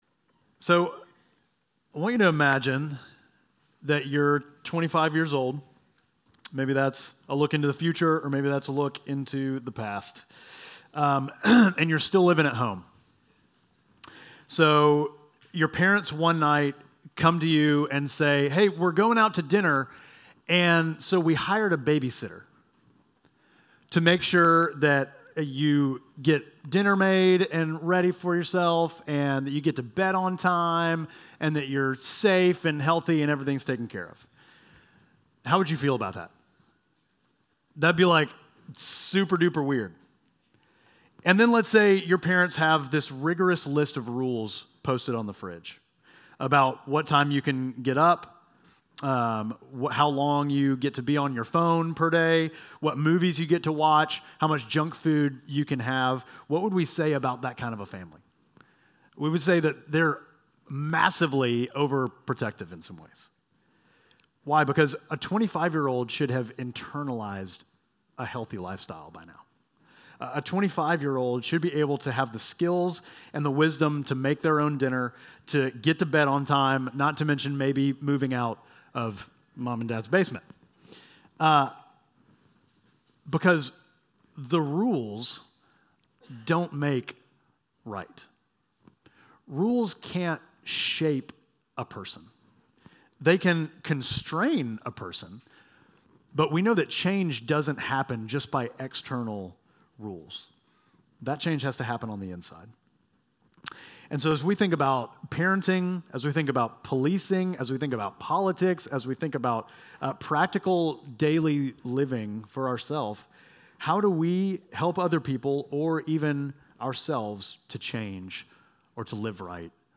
Midtown Fellowship Crieve Hall Sermons Jesus: The Merciful Oct 26 2025 | 00:36:18 1x 00:00 / 00:36:18 Subscribe Share Apple Podcasts Spotify Overcast RSS Feed Share Link Embed